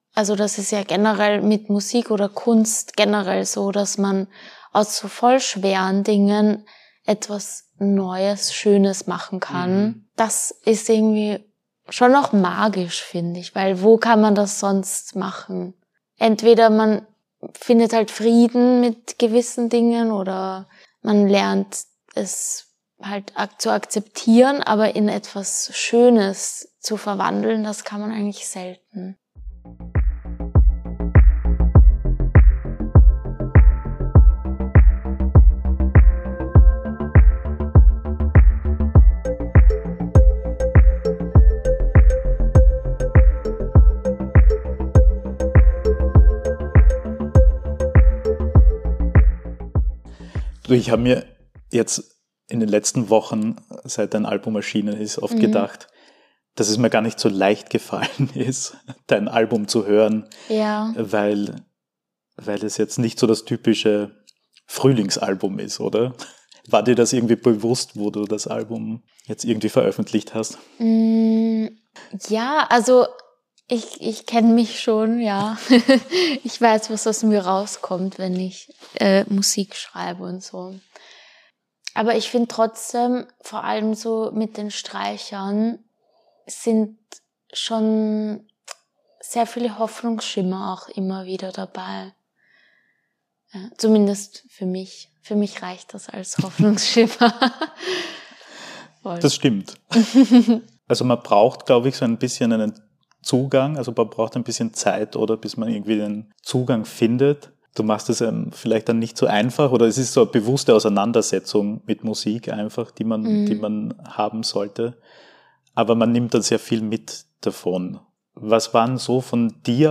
Ein Gespräch über das Ich und das Wir, über Glorifizierung und Wahrheit, über Kunst als kollektives Erinnern.